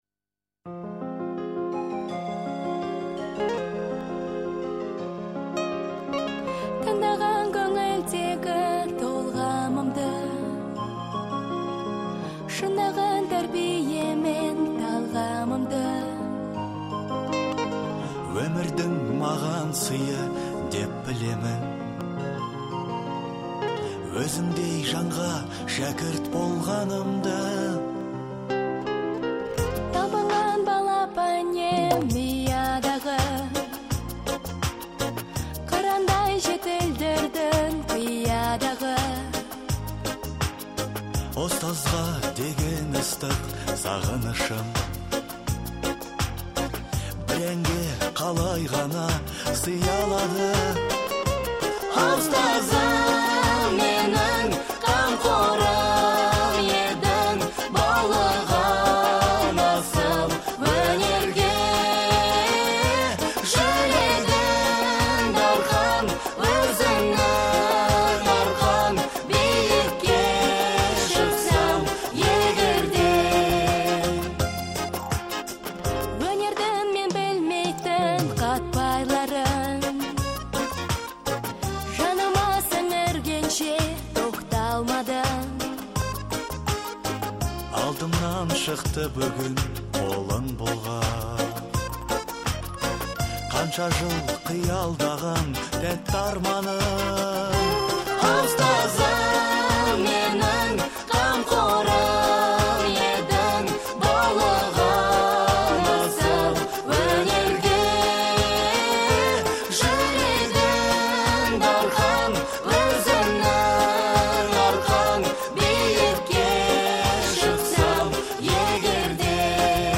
выполненная в жанре поп.